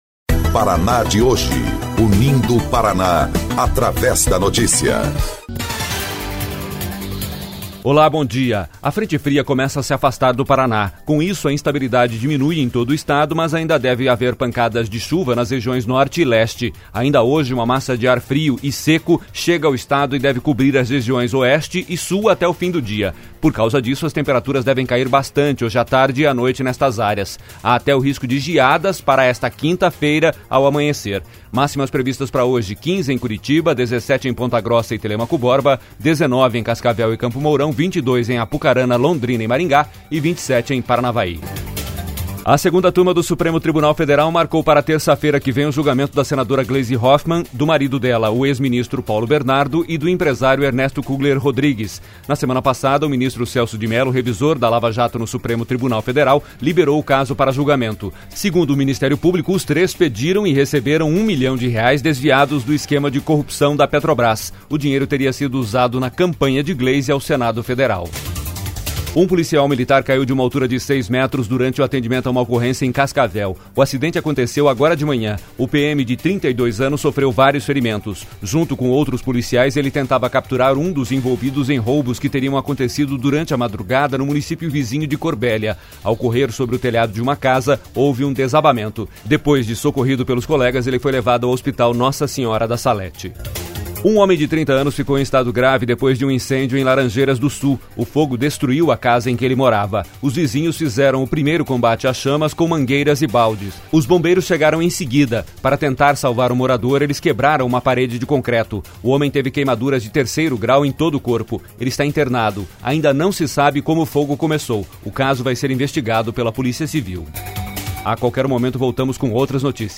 13.06 – GIRO DE NOTÍCIAS – MANHÃ